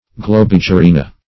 Globigerina \Glo*big`e*ri"na\, n.; pl. Globigerin[ae]. [NL.,